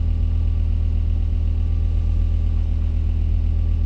rr3-assets/files/.depot/audio/Vehicles/v10_04/v10_04_idle.wav
v10_04_idle.wav